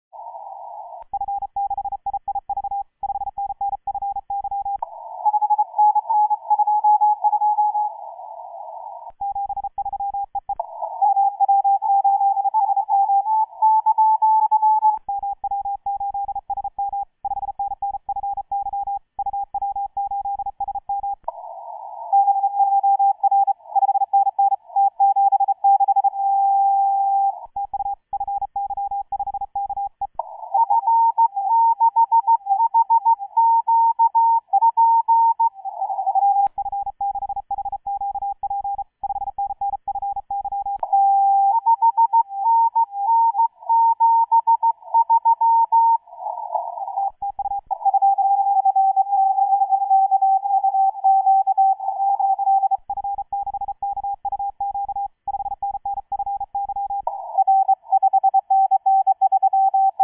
2. The art of piercing the pile-up: Analyze how your signal behaves in the middle of dozens of others. Is it “sharp” enough, higher pitched? Is your CW manipulation precise enough to emerge from the noise and be read easily?